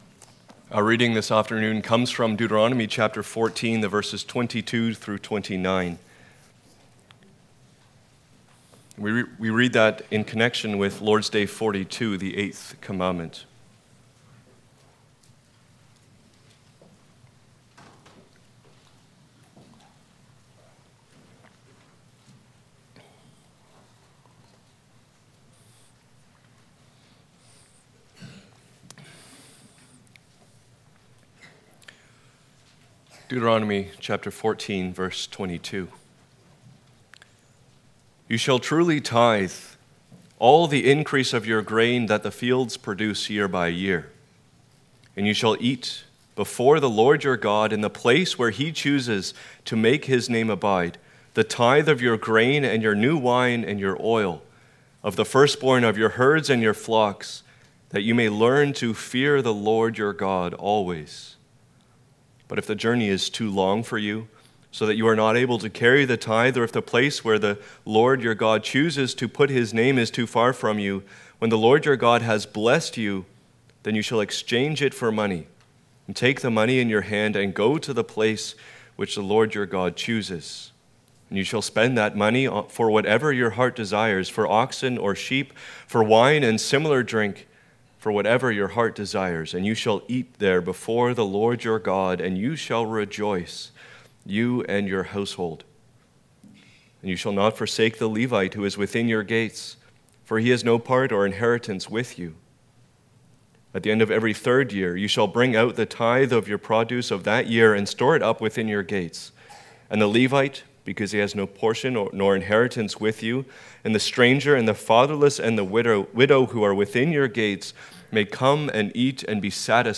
Passage: Deuteronomy 14:22-29, Lord's Day 42 Service Type: Sunday Afternoon